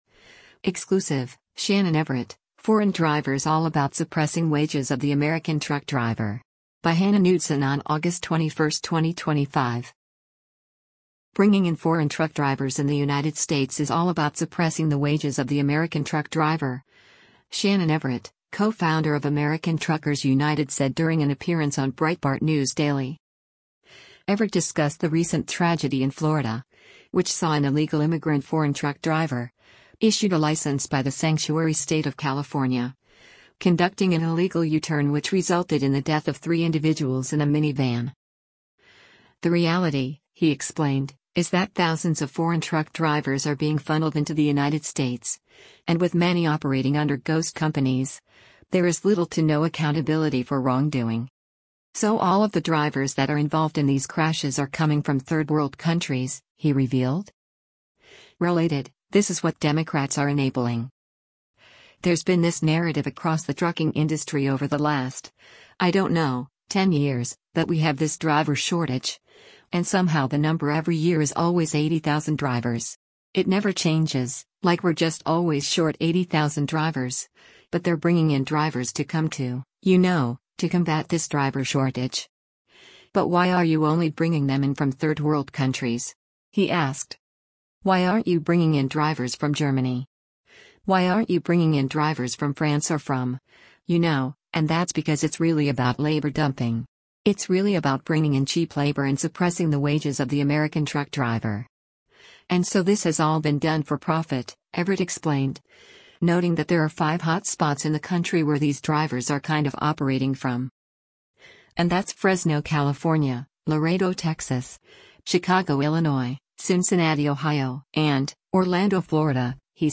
One individual called into the show and noted that Chicago is a “hotbed” for Eastern Europeans.